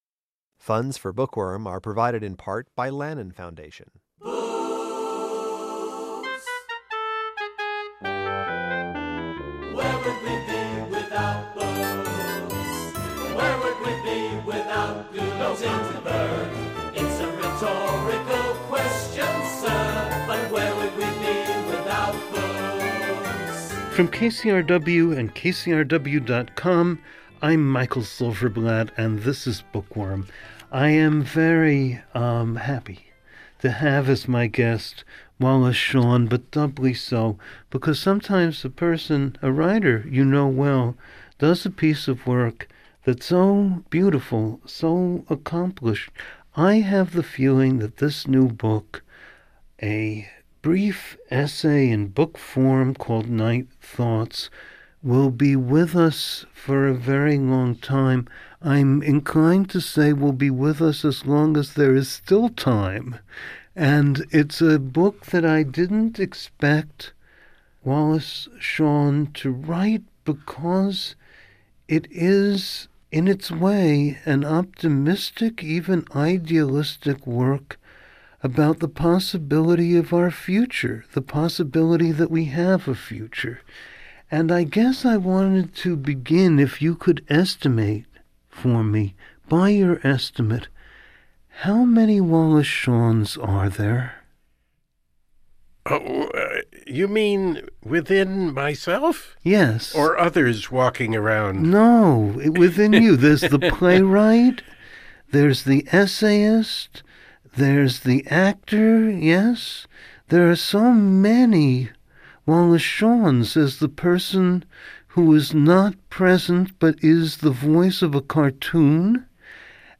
This show features a dramatic and emotional reading by writer/actor Wallace Shawn of an excerpt from Night Thoughts. According to Shawn, the world is divided between the lucky and the unlucky. His book-length essay is, in part, an apology for being a lucky, privileged American.